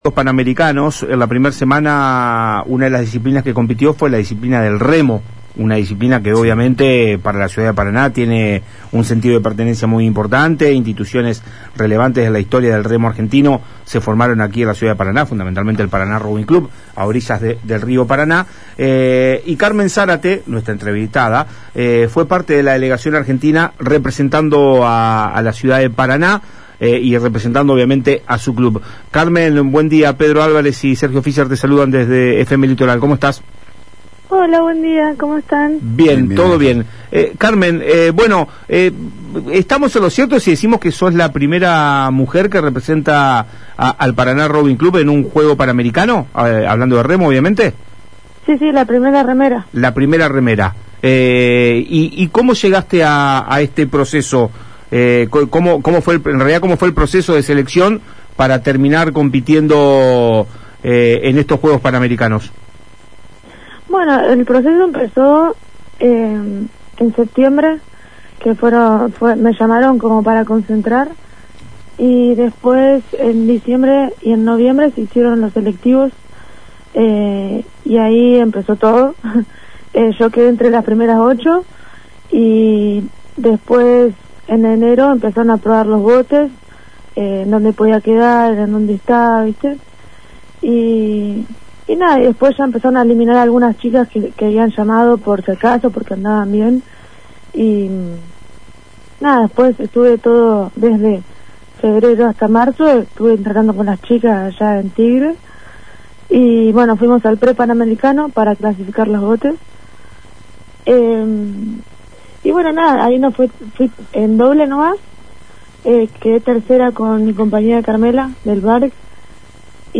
En una entrevista con Palabras Cruzadas por FM Litoral,